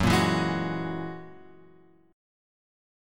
F#9 chord {2 1 2 1 2 x} chord